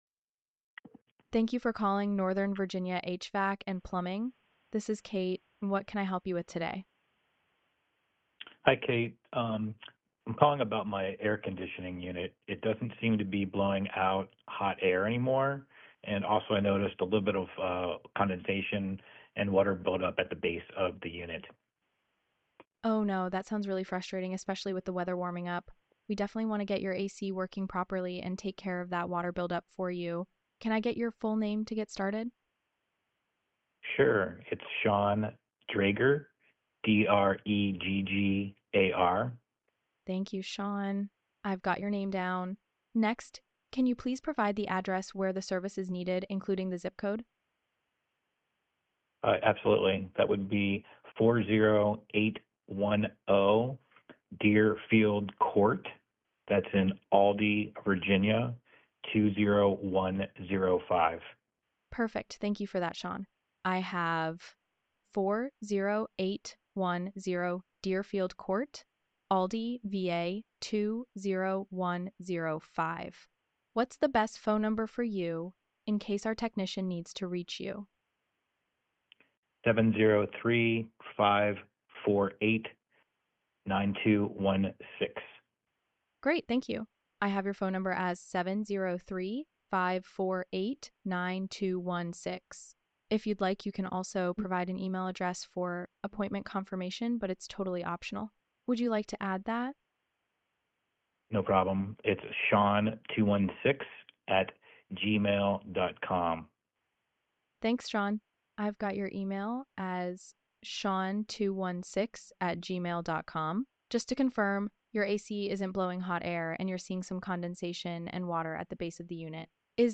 HVAC Demo · Kate · AI Voice Agent
Kate answers immediately — warm, professional, human-sounding
hvac-demo.mp3